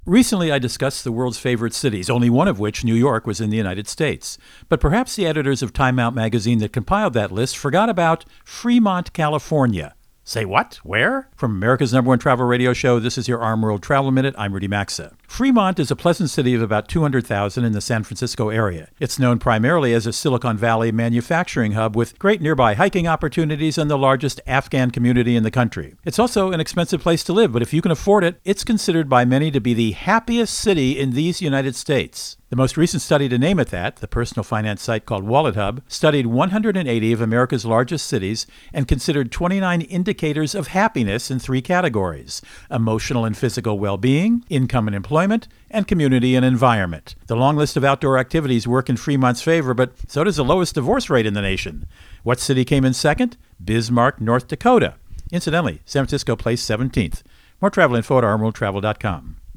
America's #1 Travel Radio Show
Co-Host Rudy Maxa | U.S. Happy Cities